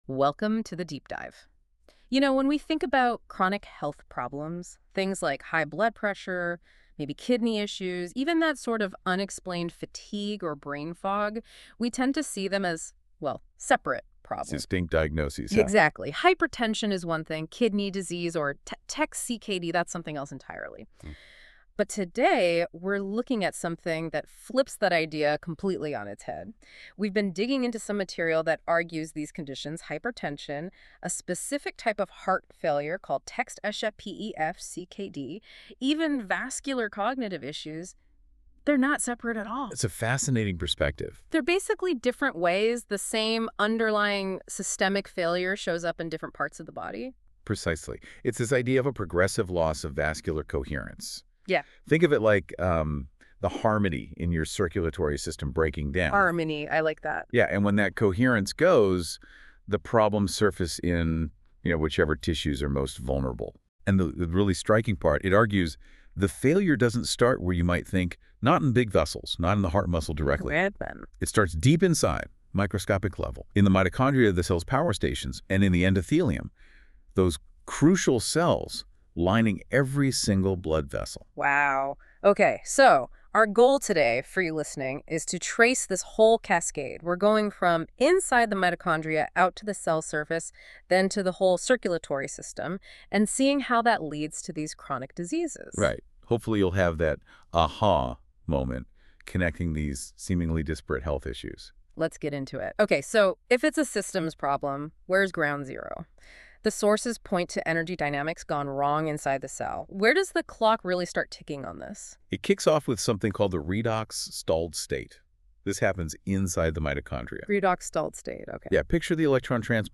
Vascular Coherence and the Unifying Pathophysiology of Chronic Disease: Mitochondrial Redox Stress, Endothelial Glycocalyx Failure, and LC Resonance Collapse | ChatGPT5 & NotebookLM - TOWARDS LIFE-KNOWLEDGE
Deep Dive Audio Overview